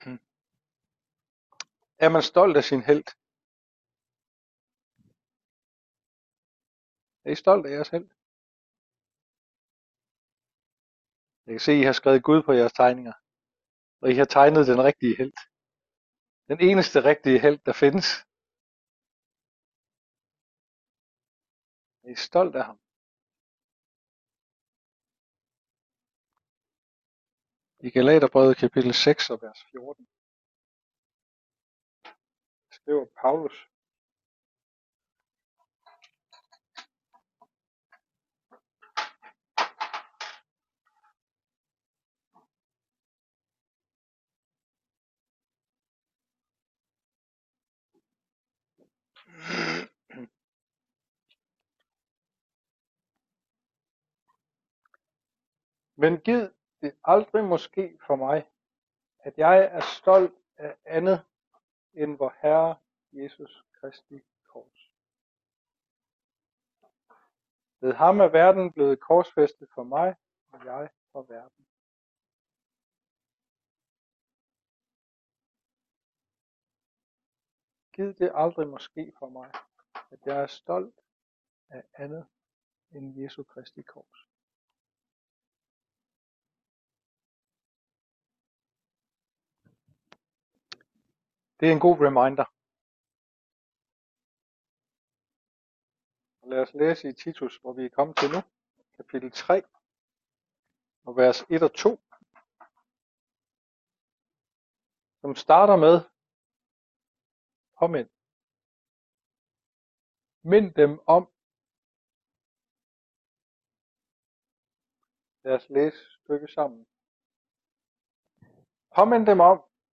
Taler